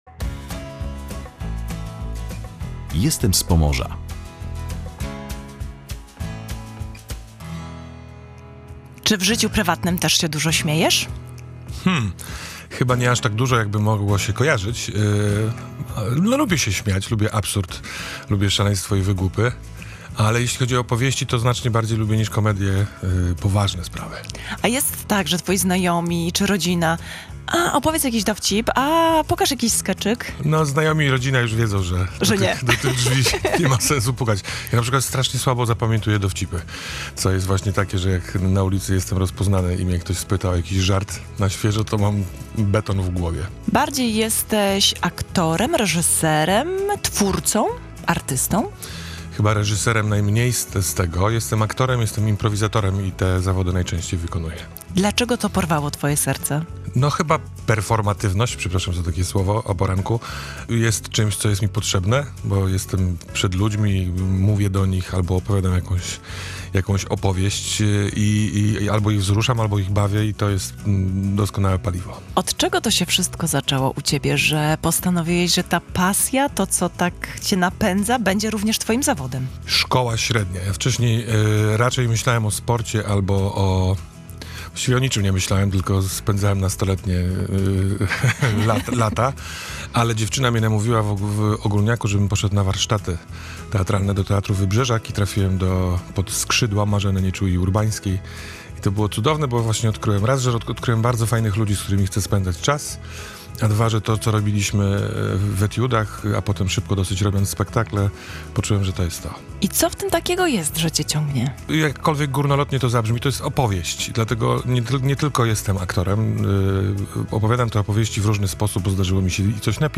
Aktor, improwizator, reżyser.